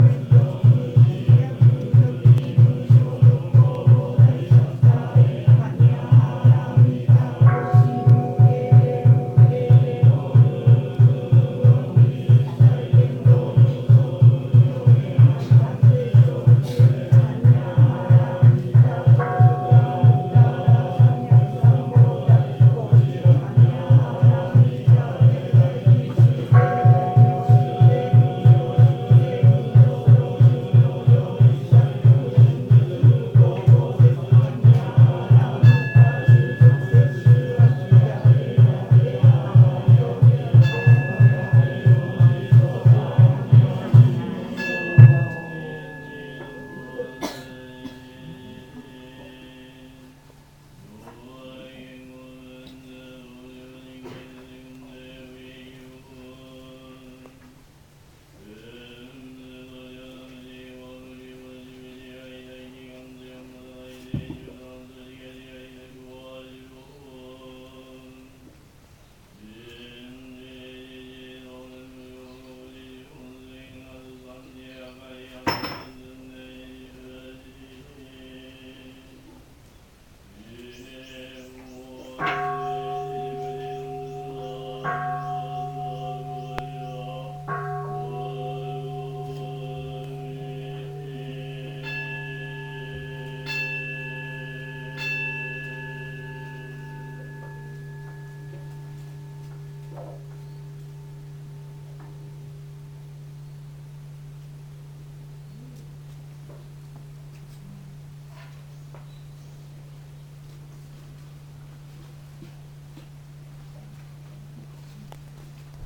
Une fois que la majorité des visiteurs à fini d'écrire le texte, le moine va lire/chanter ce sûtra d'une manière très rythmée avec un tambour et parfois des gongs et tout les visiteurs - enfin ceux qui arrivent à lire le texte ;) - se mettent à chanter avec lui (j'ai fais un enregistrement tellement s'était surprenant... j'adore!! le
voici - désolé pour la mauvaise qualité mais j'avais juste mon téléphone sur moi... c'est par terrible pour enregistrer du son à 15m...).